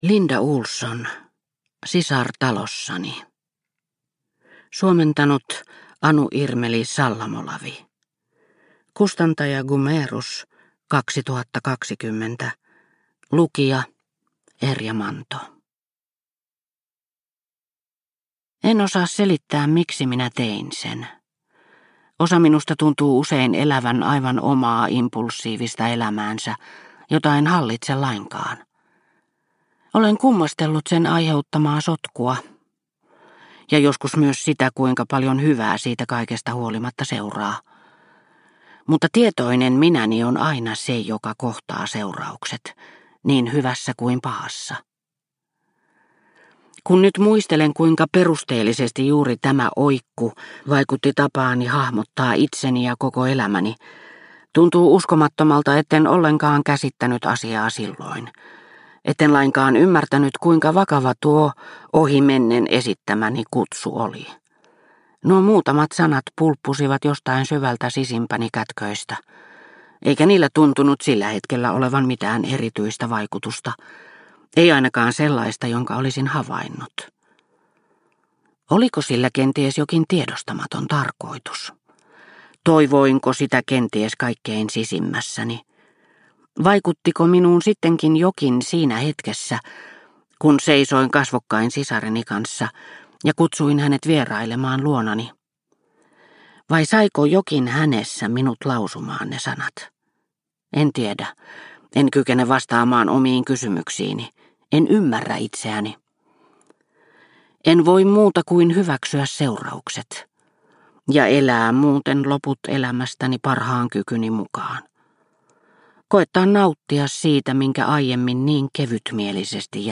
Sisar talossani – Ljudbok – Laddas ner